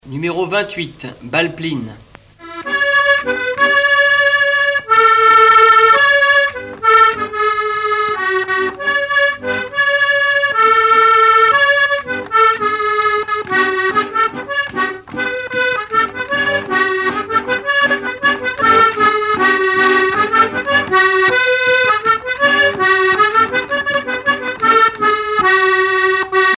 danse : bal (Bretagne)
Pièce musicale éditée